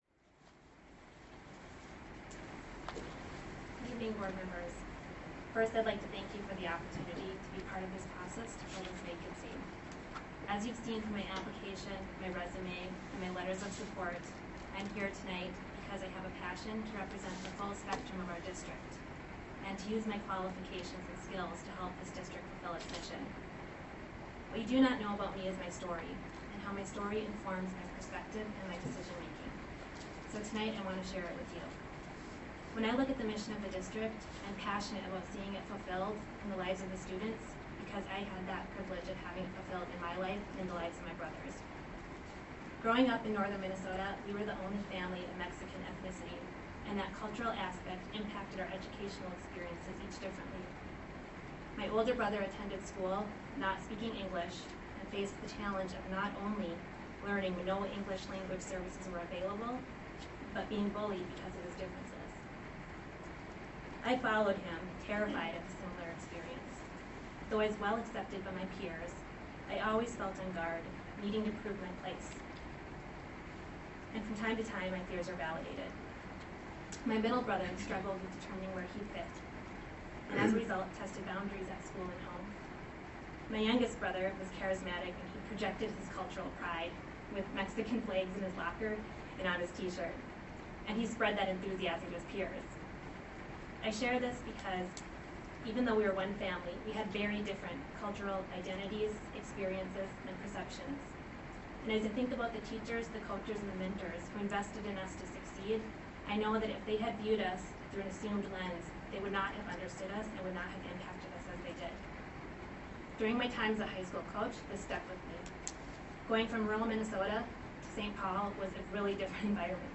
Appointment Candidate Address